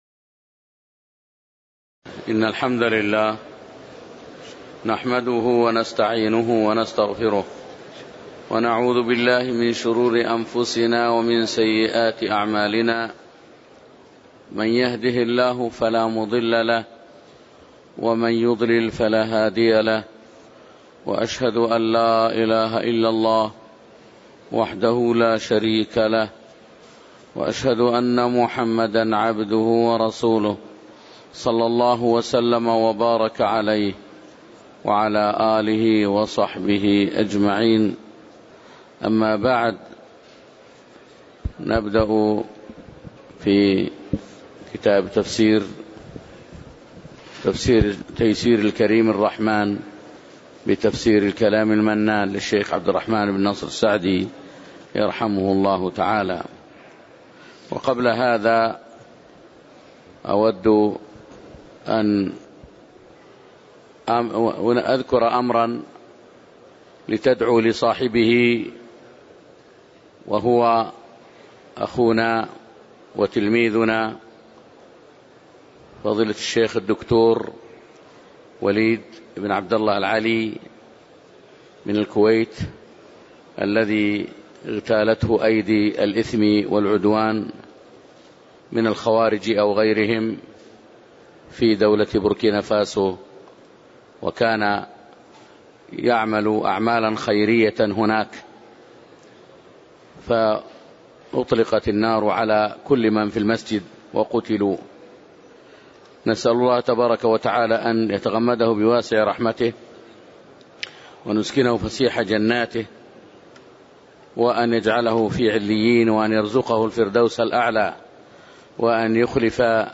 تاريخ النشر ٢٦ ذو القعدة ١٤٣٨ هـ المكان: المسجد النبوي الشيخ